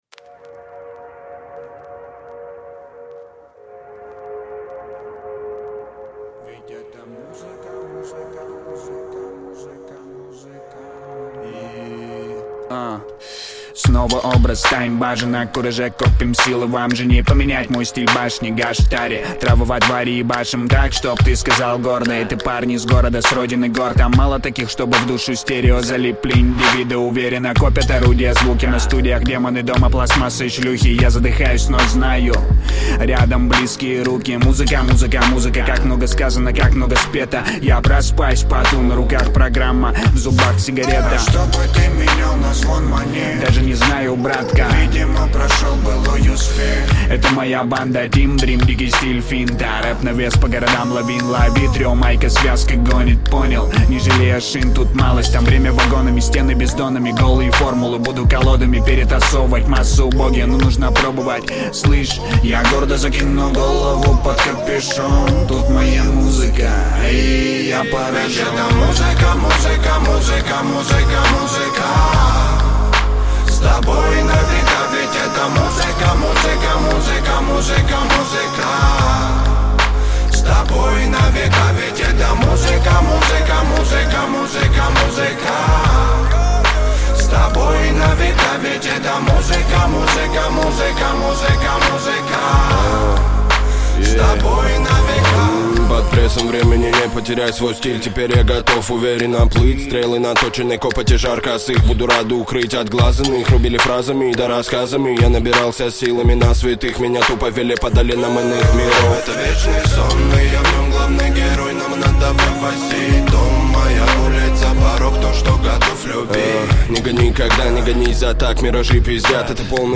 Русский рэп
Жанр: Жанры / Русский рэп